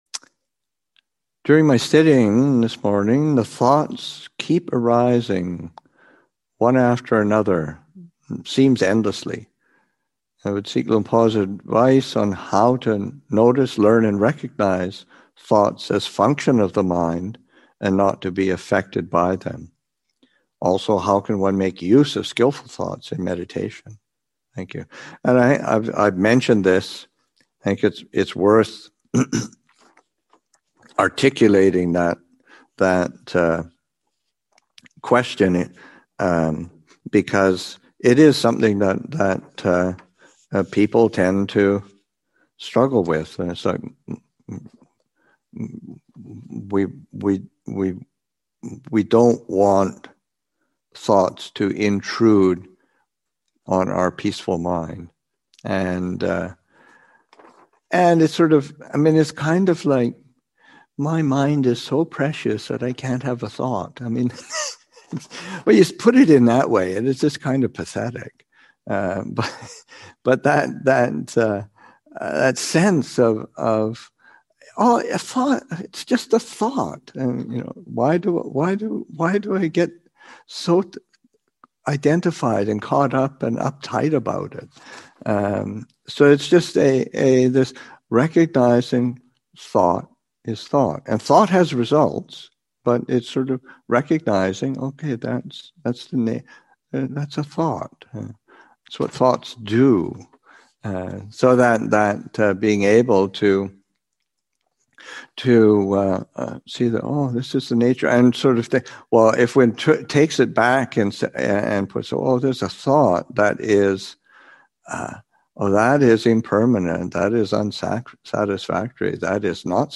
Awaken to the New Year Retreat, Session 2 – Jan. 1, 2021